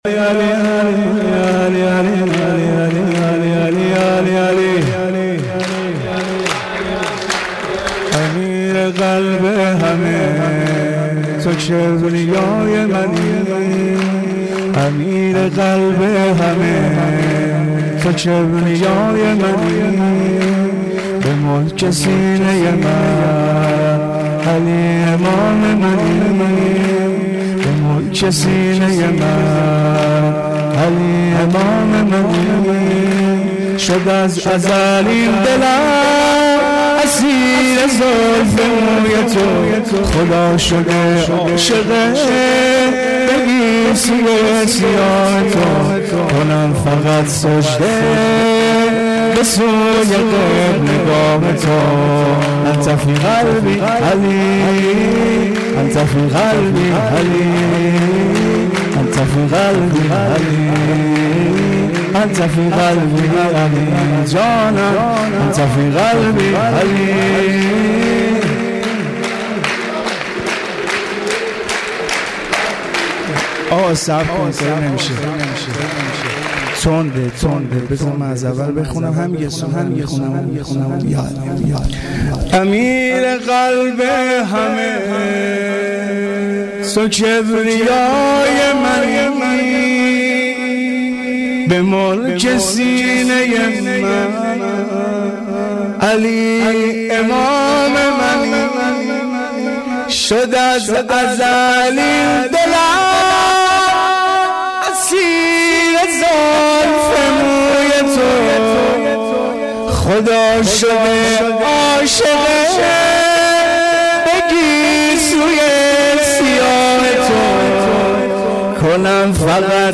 دانلود مولودی جدید, سرود غدیر